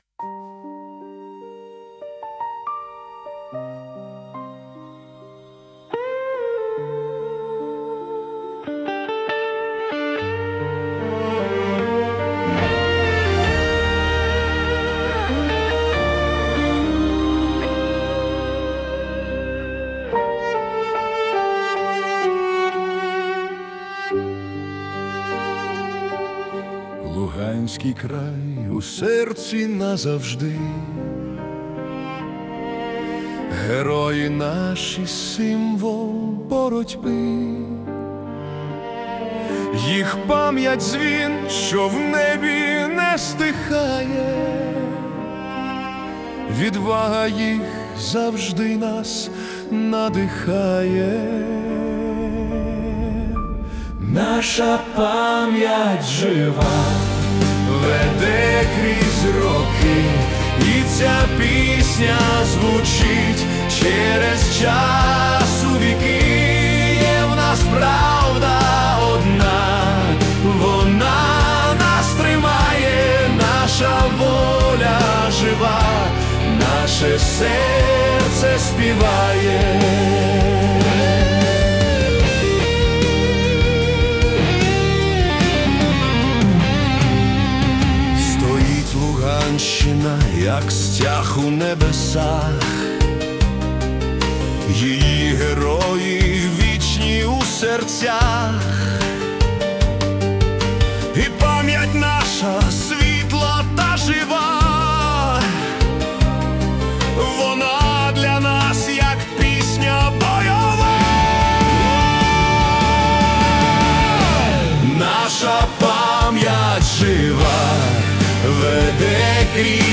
Chamber Music / Acoustic Pop
Попри м'якість звучання, текст несе в собі незламну силу.